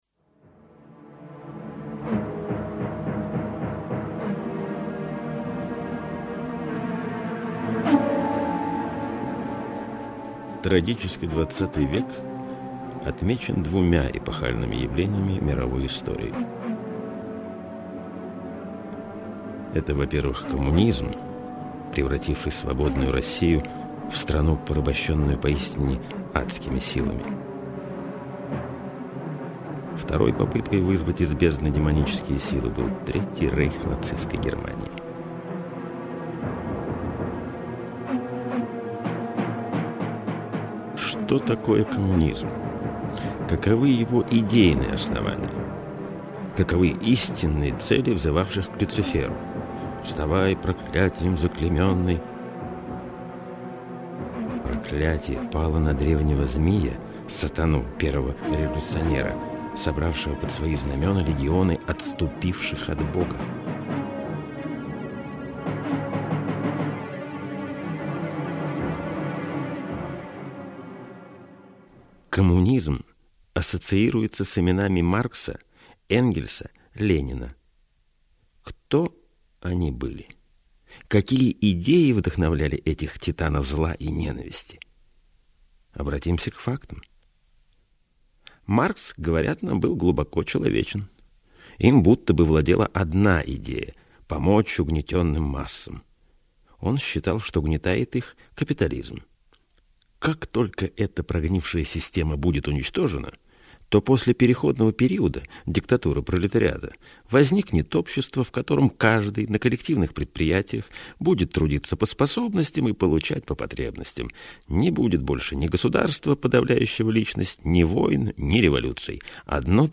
Аудиокнига Великие идеи ХХ века | Библиотека аудиокниг